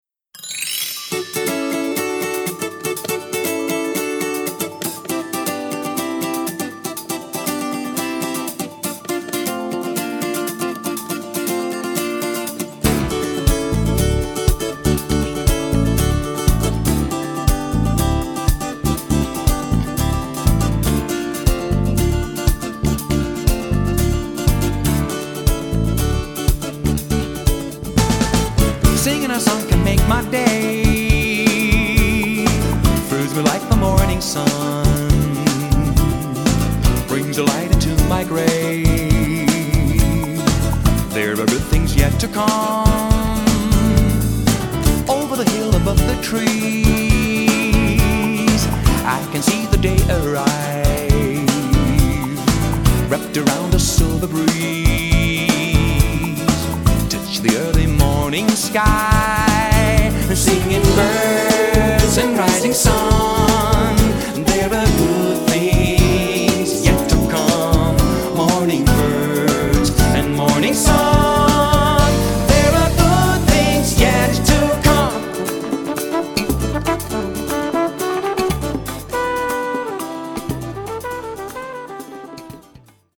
空に舞い上がりそうな幸せ感だよね。